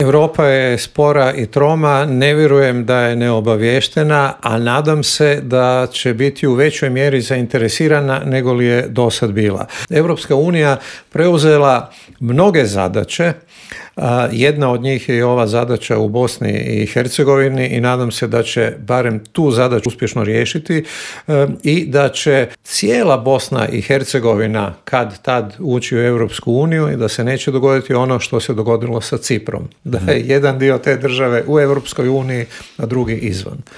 Zašto su izbori na Tajvanu prijetnja svjetskom miru i zašto su izgledi za okončanja rata u Ukrajini ove godine mali, pitanja su na koje smo odgovore tražili u intervuju Media servisa s analitičarom Božom Kovačevićem.